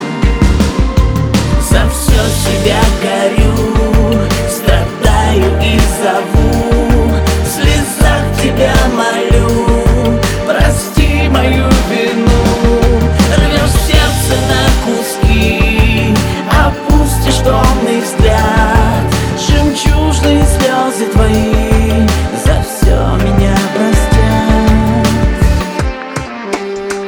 • Качество: 320, Stereo
грустные
русский шансон